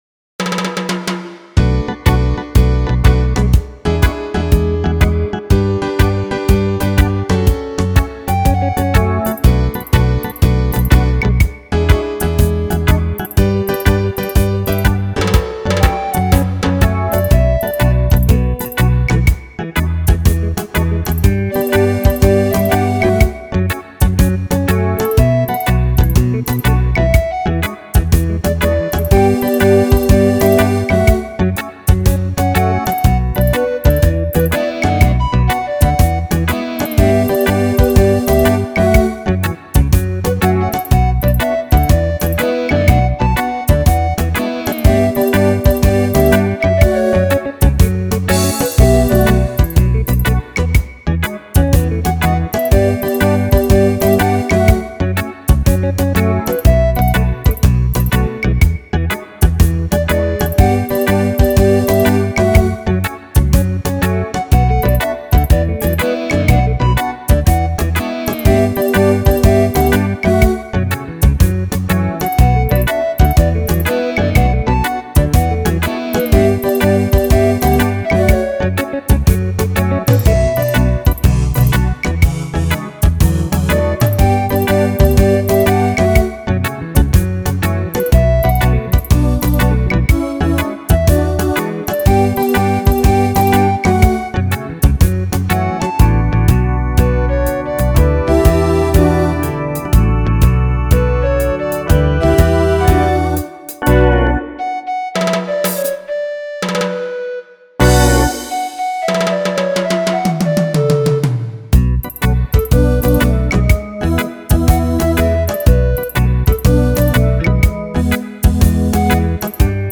th89J9GKBT  Download Instrumental
There’s no happier music in the world than Reggae.